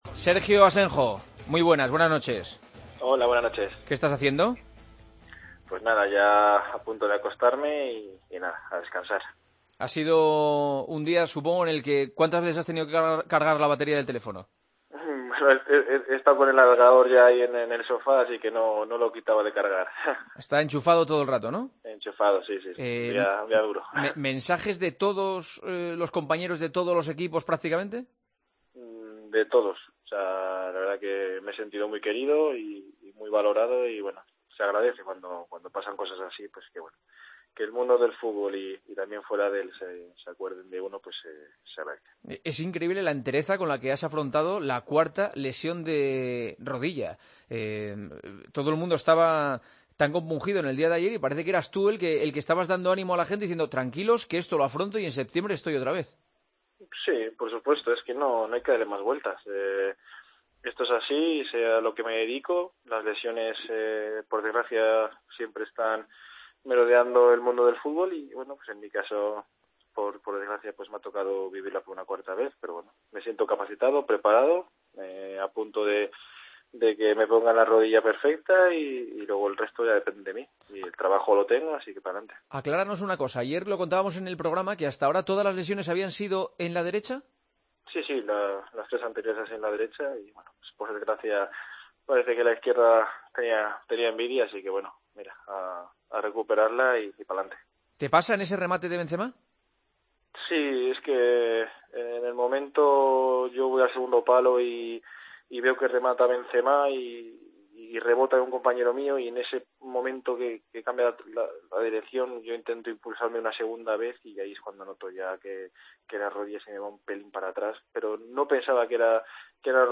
Juanma Castaño comenzó El Partidazo de COPE de este lunes con una llamada de ánimo al portero del Villarreal, lesionado de la rodilla por cuarta vez en su carrera: "Hoy me he sentido valorado y querido.